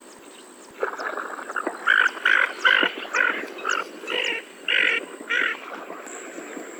メジロガモ
【鳴き声】地鳴き 【聞きなし】「カッ」「キュッ」